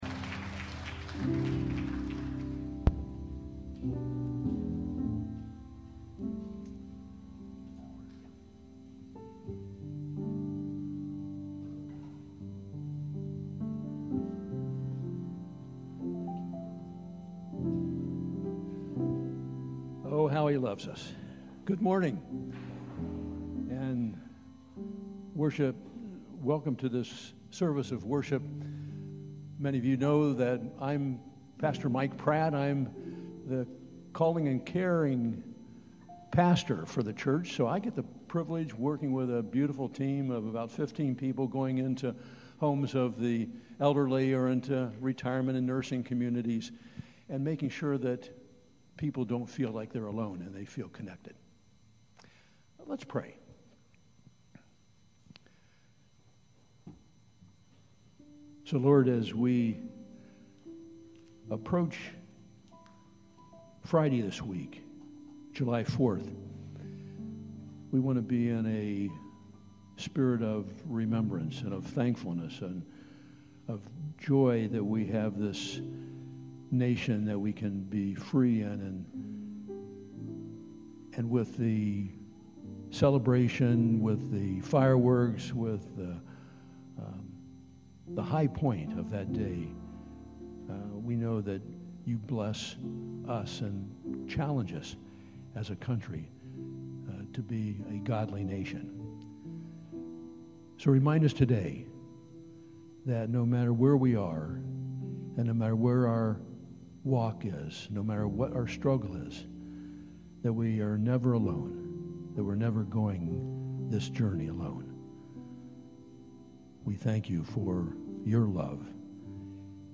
9:30 AM Redemption Worship Service June 29, 2025